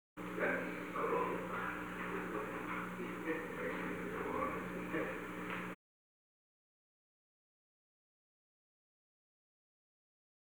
Conversation: 906-026
Recording Device: Oval Office
The Oval Office taping system captured this recording, which is known as Conversation 906-026 of the White House Tapes.
Henry A. Kissinger met with an unknown man.